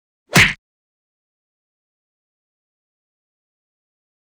赤手空拳击中肉体3-YS070524.wav
通用动作/01人物/03武术动作类/空拳打斗/赤手空拳击中肉体3-YS070524.wav
• 声道 立體聲 (2ch)